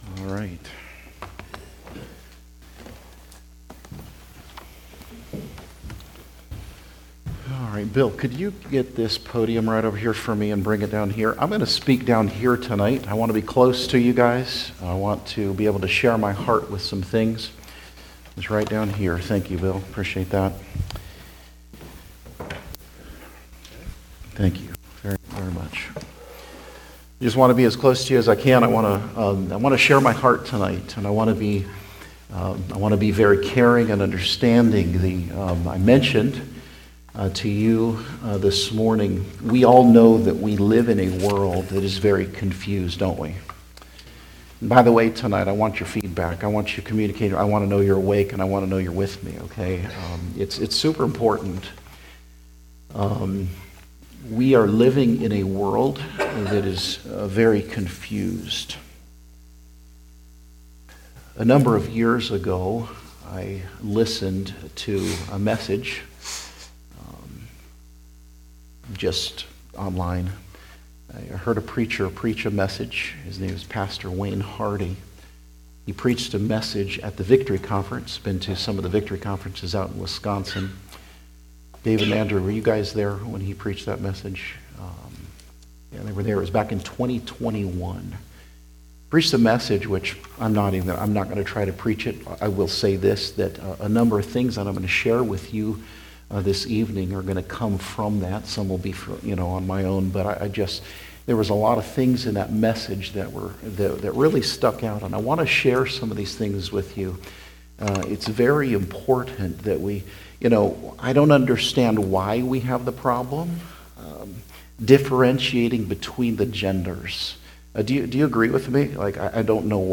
Passage: Genesis 1:27 Service Type: Evening Service